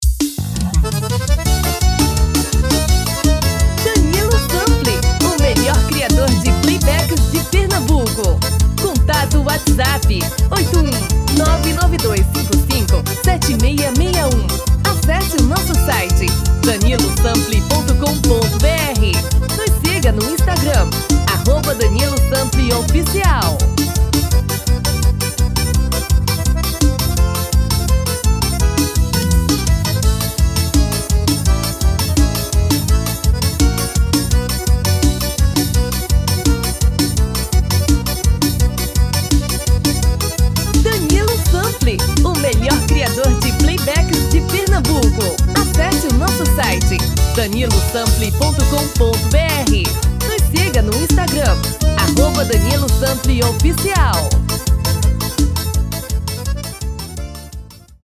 DEMO 1: tom original / DEMO 2: tom masculino